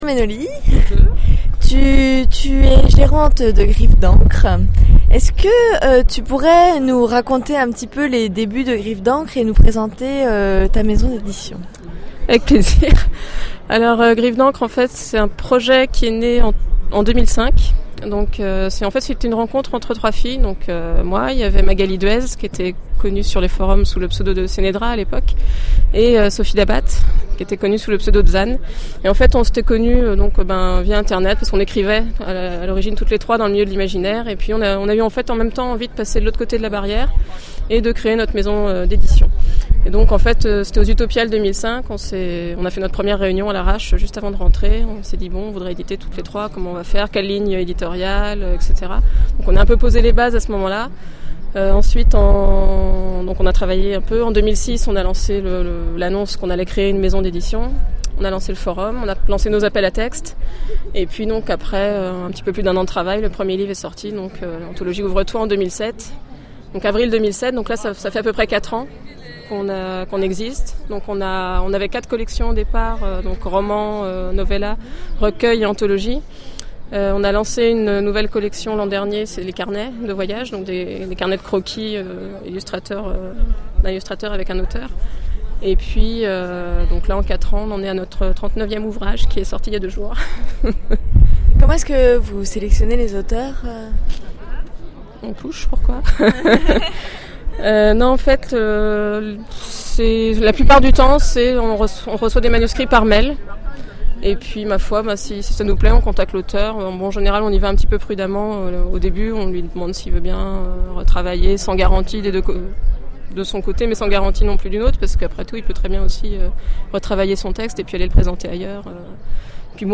ITW Griffe d'Encre